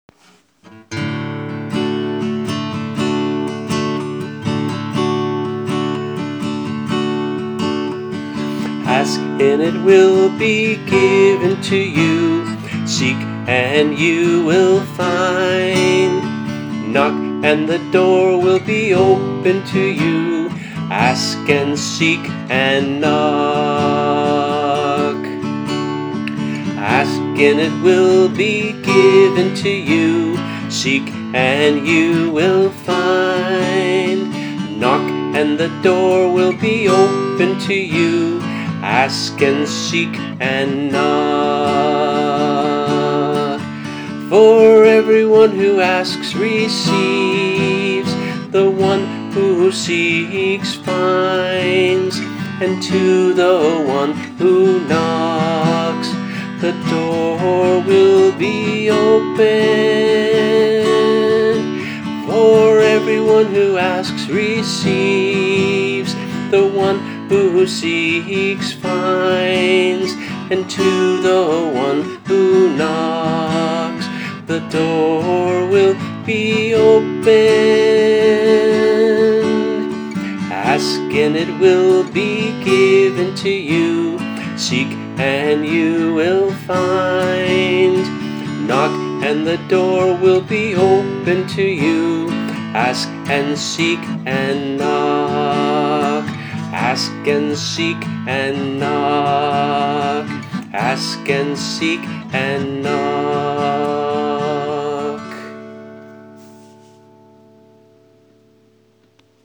[MP3 - voice and guitar]